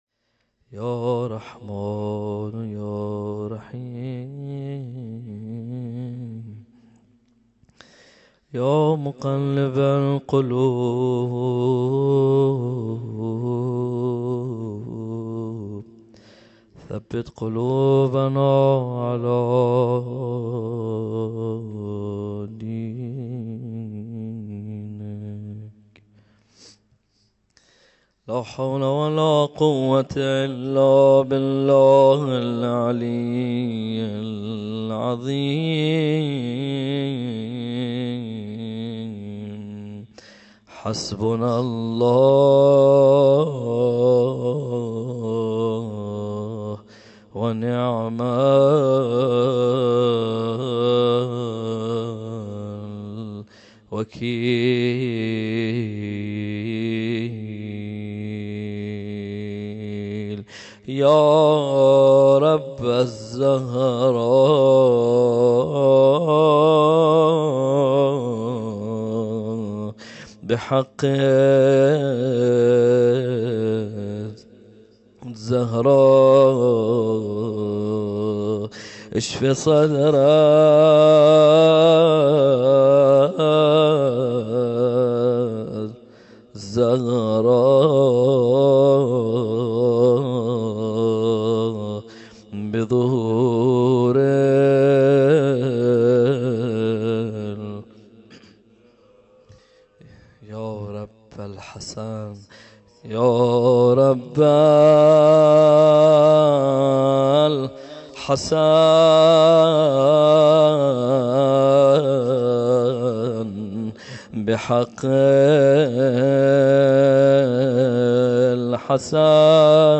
روضه و مداحی | یه سال منتظریم
شب دهم محرم الحرام 1442 ه.ق | هیأت علی اکبر بحرین